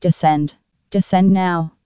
TCAS voice sound samples. ... Artificial female voice.
descend_now.wav